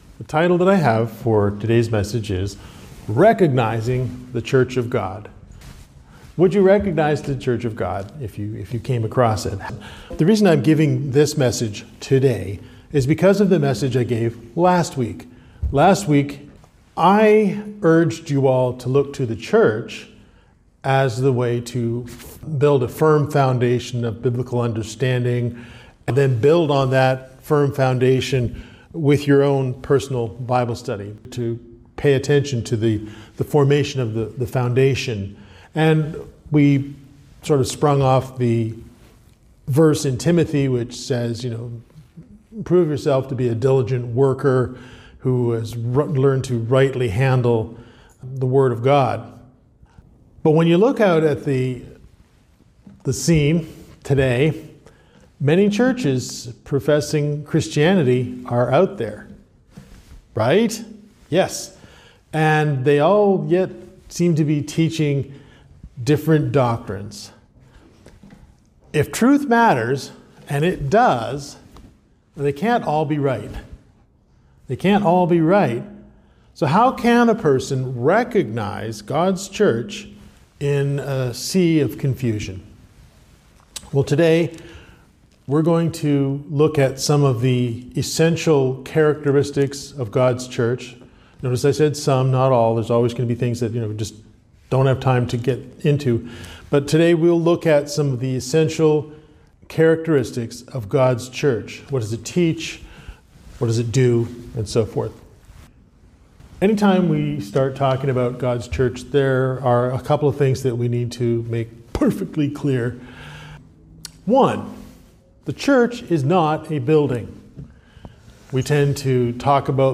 This is part of a series of related sermons that build on each other.